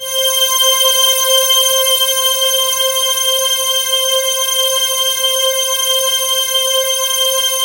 PAD3  C5  -R.wav